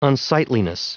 Prononciation du mot unsightliness en anglais (fichier audio)
Prononciation du mot : unsightliness